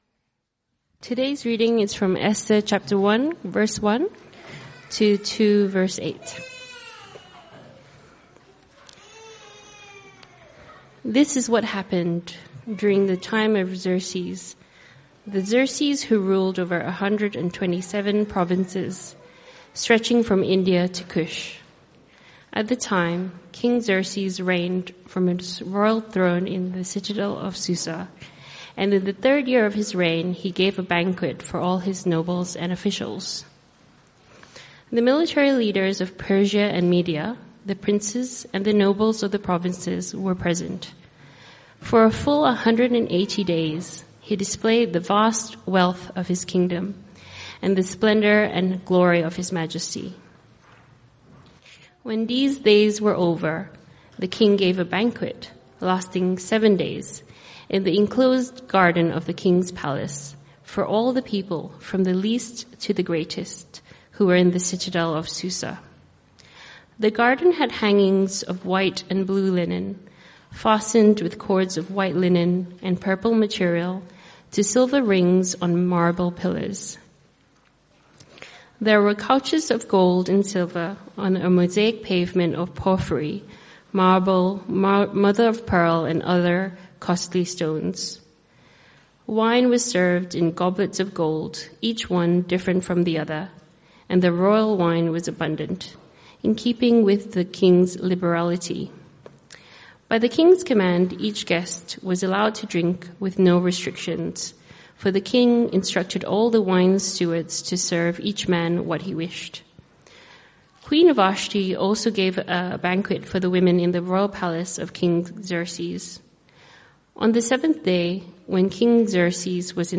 Esther 1-2 Type: Sermons CBC Service